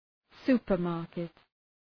Shkrimi fonetik{‘su:pər,mɑ:rkıt}